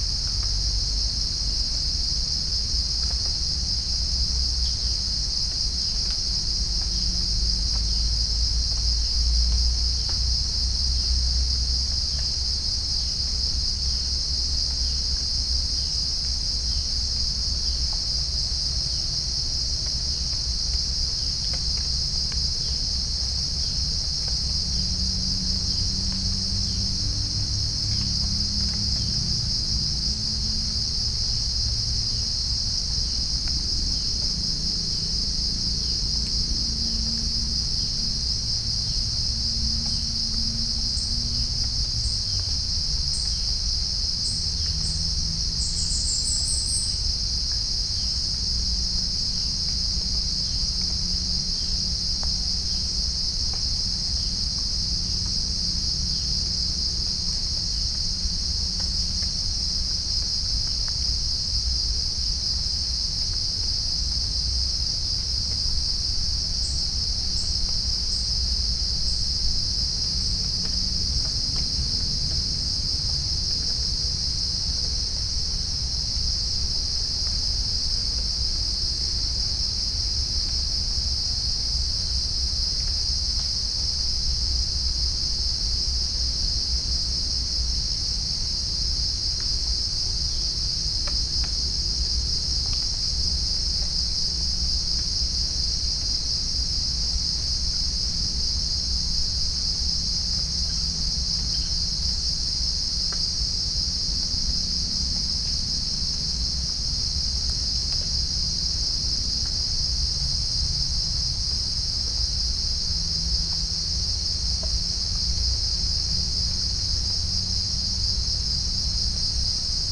Chalcophaps indica
Pycnonotus goiavier
Pycnonotus aurigaster
Halcyon smyrnensis
Orthotomus ruficeps
Todiramphus chloris
Dicaeum trigonostigma